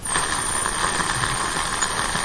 冲击、击打、摩擦 工具 " 螺母枪慢1
Tag: 工具 工具 崩溃 砰的一声 塑料 摩擦 金属 冲击